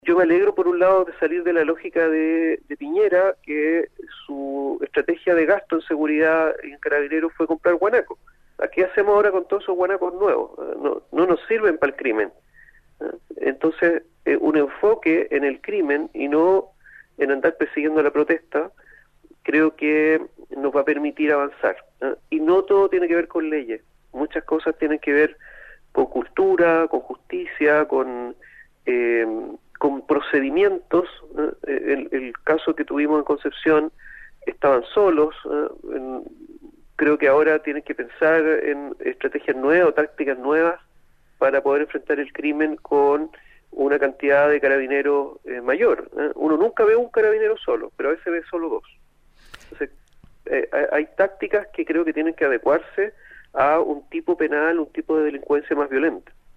En Nuestra Pauta conversamos con el diputado del Distrito 20, Félix González Gatica, sobre la Agenda de Seguridad que implica la tramitación de diferentes iniciativas legales en el Congreso, tal como acordaron los Presidentes de ambas Cámaras junto al Gobierno.